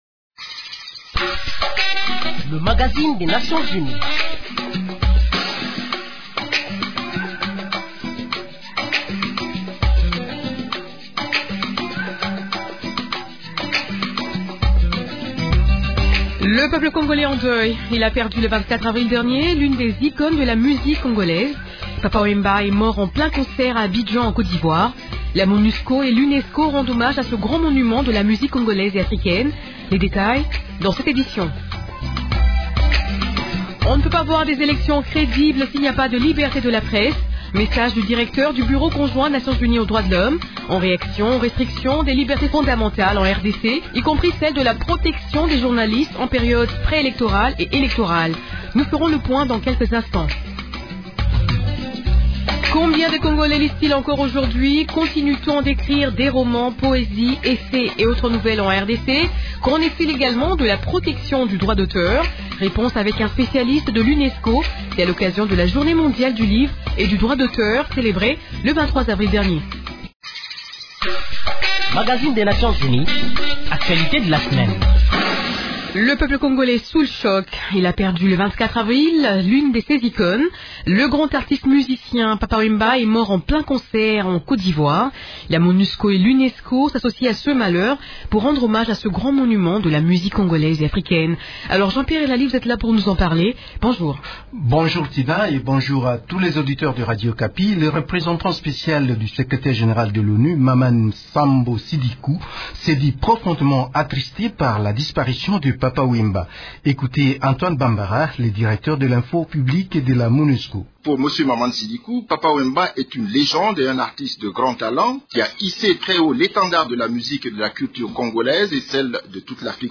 La conférence de presse hebdomadaire de mercredi dernier a été animée par téléconférence